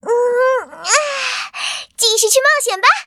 文件 文件历史 文件用途 全域文件用途 Fifi_fw_01.ogg （Ogg Vorbis声音文件，长度3.1秒，102 kbps，文件大小：38 KB） 源地址:游戏语音 文件历史 点击某个日期/时间查看对应时刻的文件。